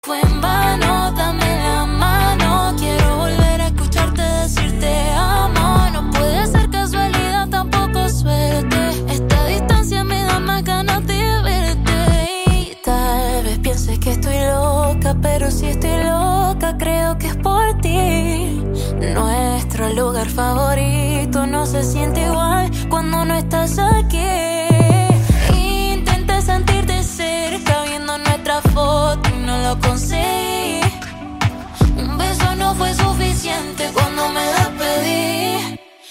Categoria POP